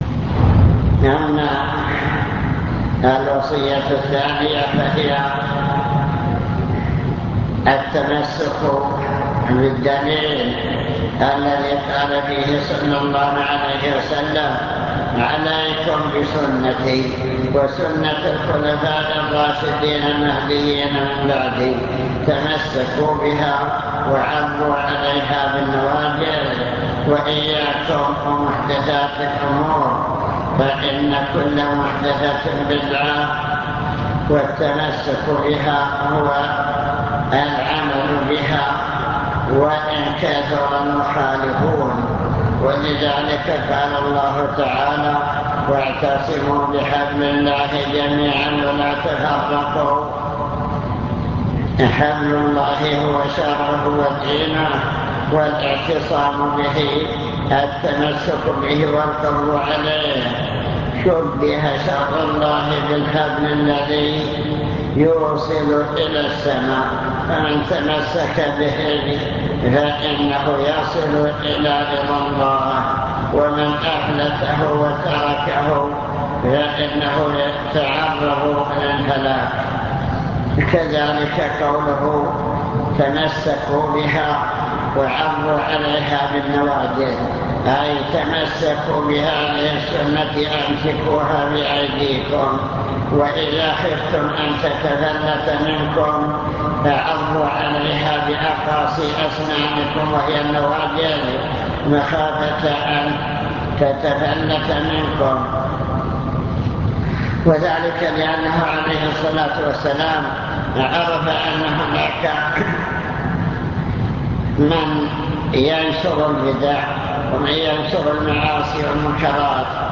المكتبة الصوتية  تسجيلات - محاضرات ودروس  محاضرة في الزلفى مع شرح لأبواب من كتاب الجنائز في صحيح البخاري